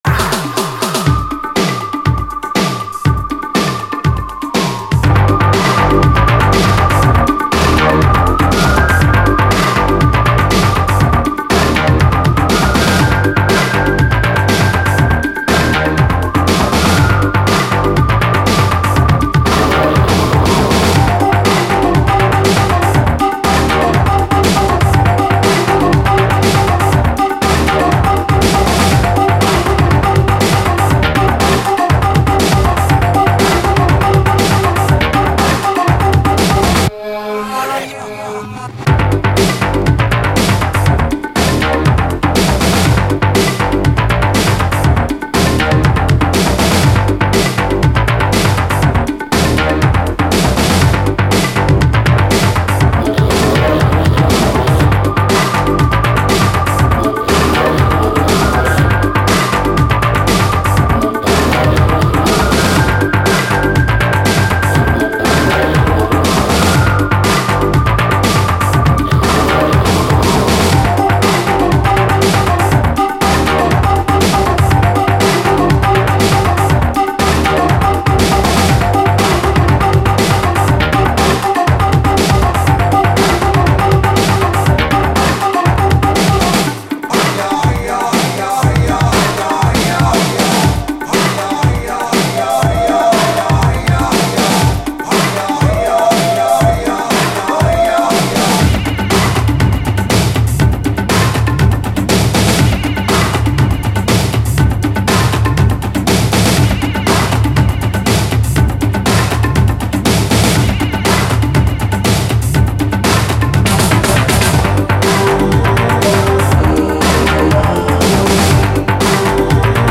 奇跡的なメロウ・トロピカル・ステッパー
UKラヴァーズの感触をダンサブルなメロウ・ディスコに落とし込んだ最高のインスト・ディスコ・レゲエ！
しなやかなメロディーに魅了されるラヴァーズ・チューン
リラクシンなメロウ・グルーヴ
レゲエの枠に収まらない洒落たサウンドが全編に漂う素晴らしいアルバム！